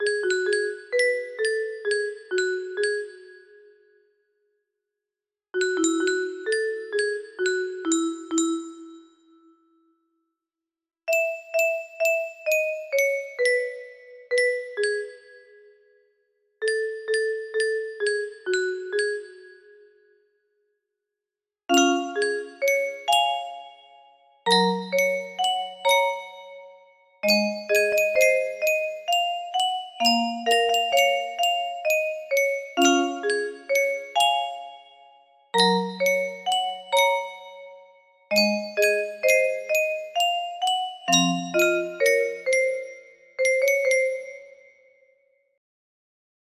Clone of Unknown Artist - Untitled music box melody
Full range 60